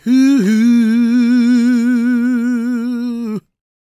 GOSPMALE025.wav